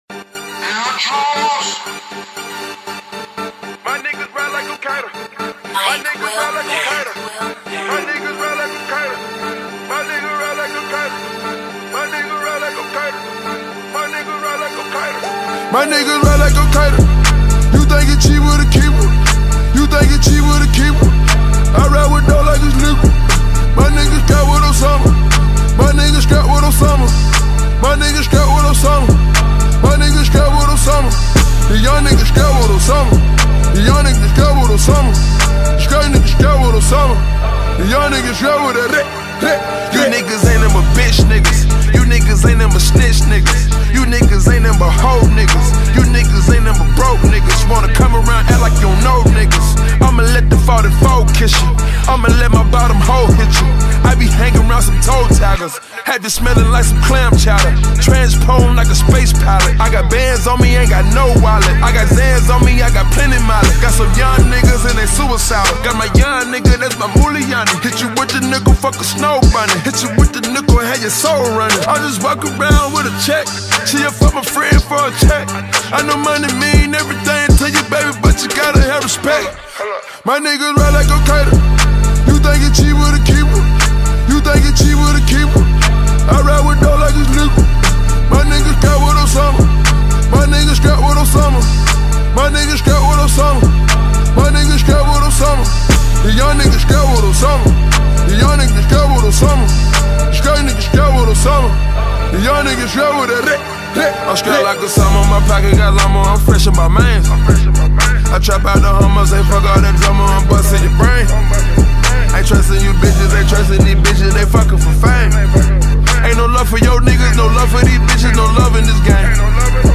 Atlanta rapper